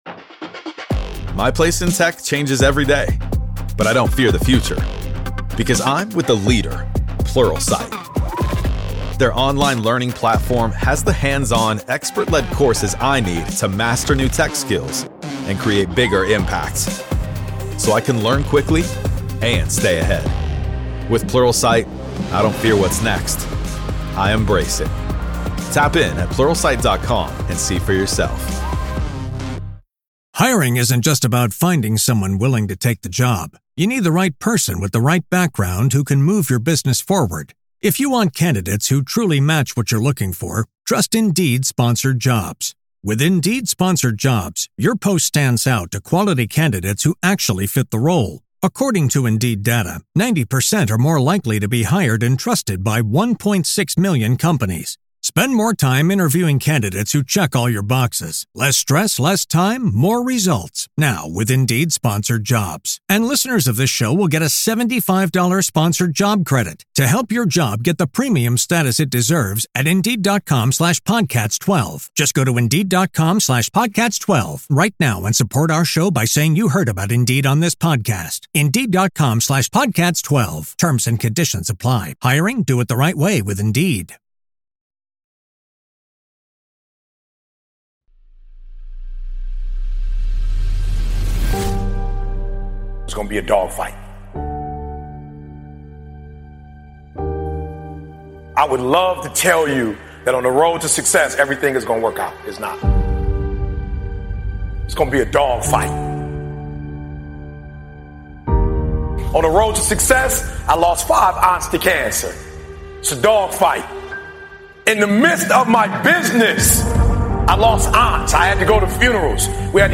Speakers: Eric Thomas